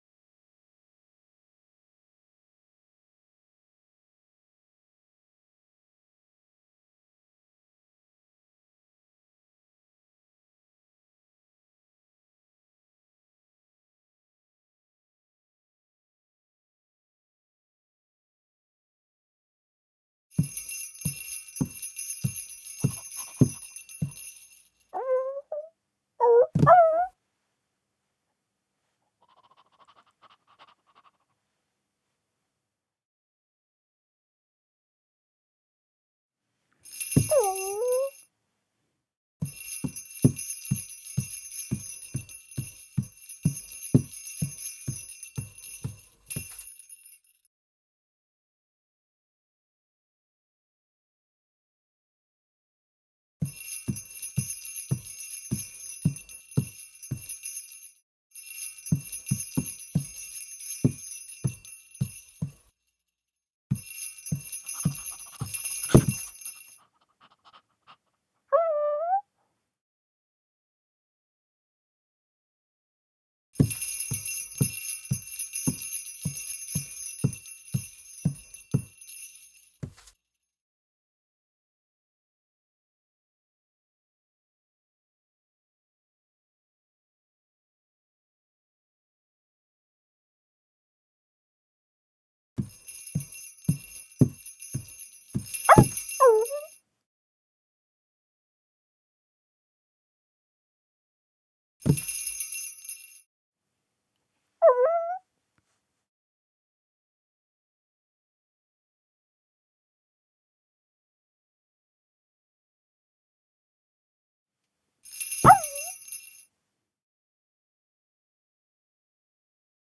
NPC_Scene04_Dog.ogg